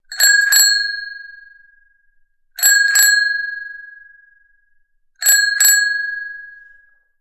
Звуки велосипедного звонка
Звук ретро звонка у велосипеда